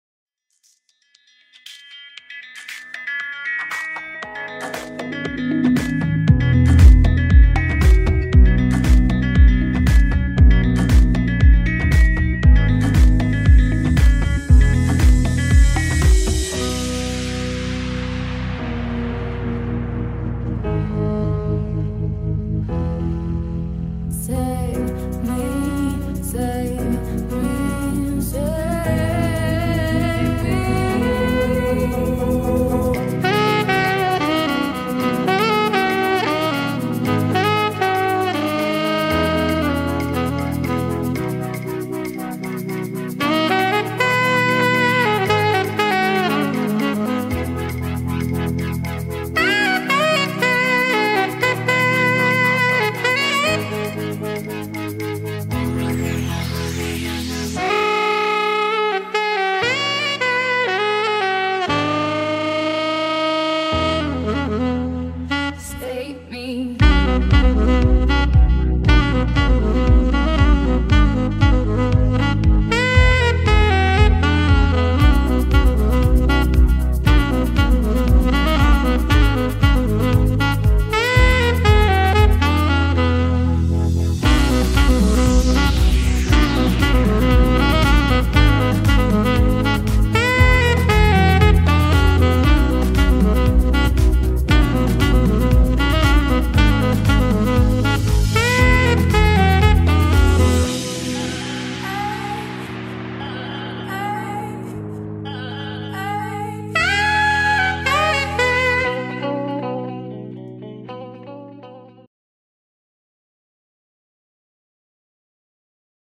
Open format, can play any genres and style.